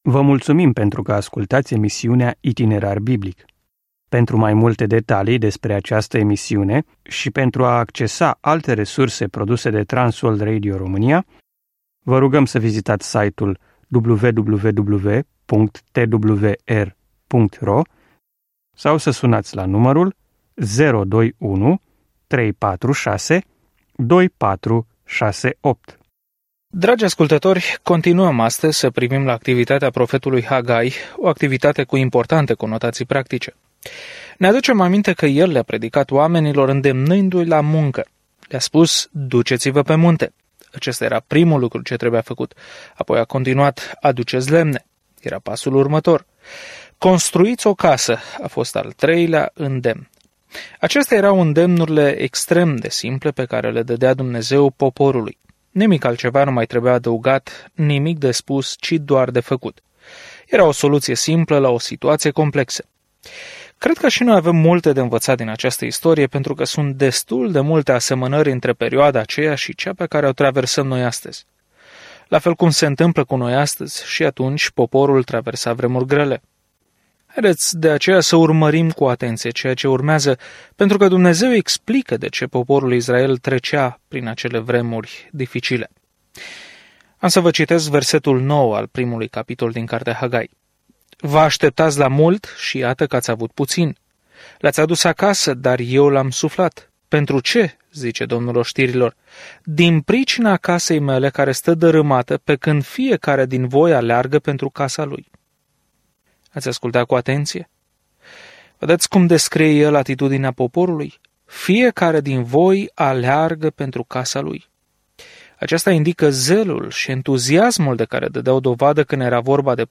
Scriptura Hagai 1:9-15 Hagai 2:1-4 Ziua 2 Începe acest plan Ziua 4 Despre acest plan Atitudinea lui Hagai „termină-l” îndeamnă un Israel distras să obțină reconstruirea templului după ce se întorc din captivitate. Călătoriți zilnic prin Hagai în timp ce ascultați studiul audio și citiți versete selectate din Cuvântul lui Dumnezeu.